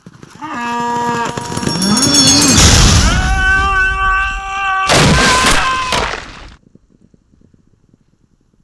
punt.wav